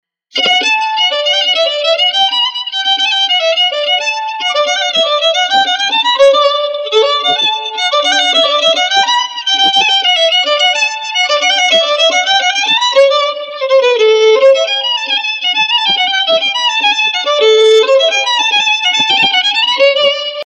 skrzypce
skrzypce.mp3